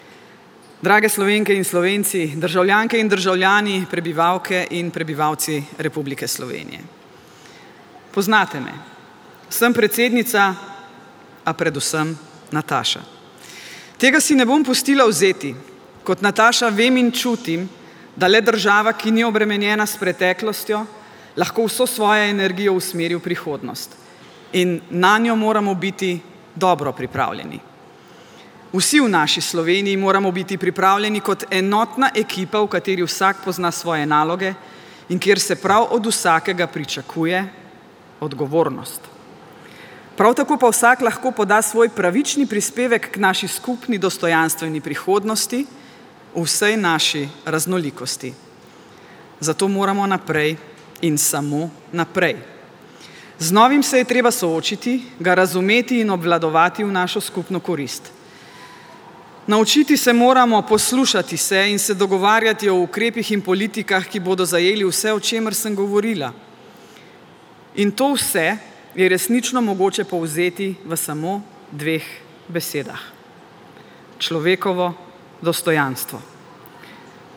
V svojem govoru se je predsednica zazrla tudi v prihodnost.
Predsednica republike Nataša Pirc Musar o tem, da je treba gledati v prihodnost in se dogovoriti za politike, ki bodo zagotavljale človekovo dostojanstvo